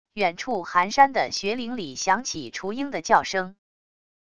远处寒山的雪岭里响起雏鹰的叫声wav音频